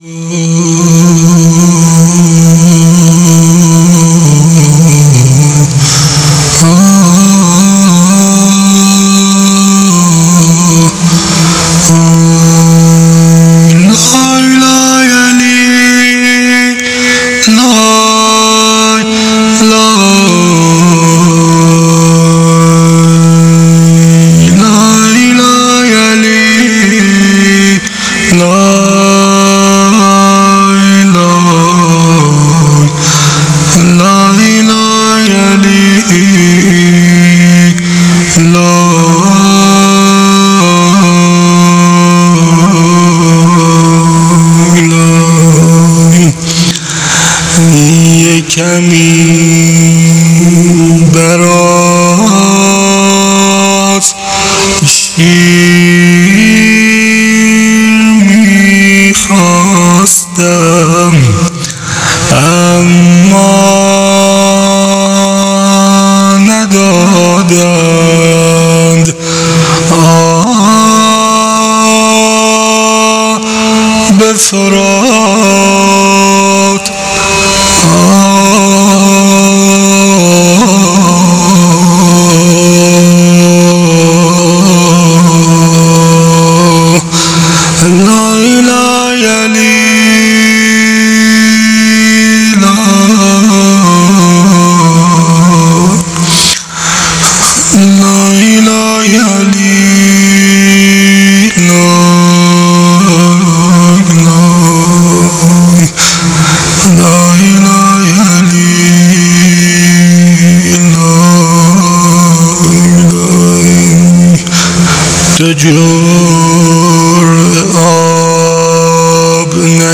روضه حضرت علی اصغر علیه السلام